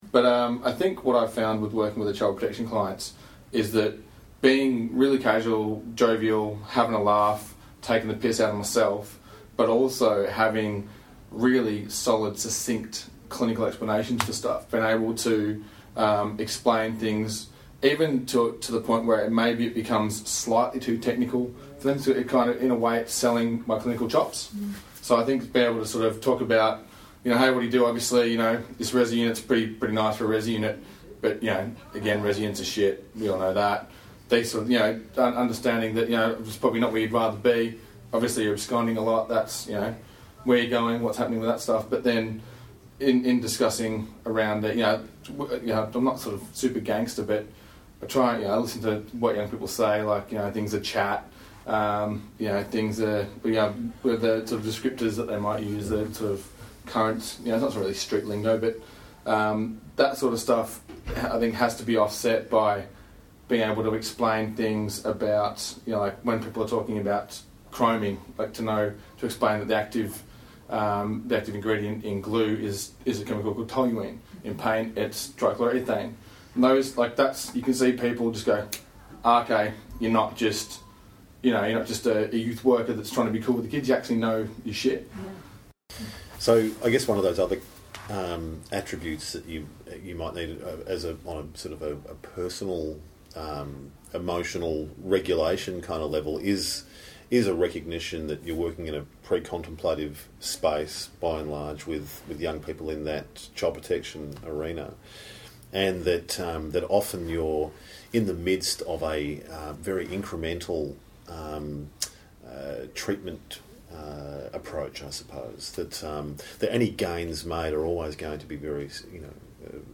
Youth worker on communicating with child protection clients.mp3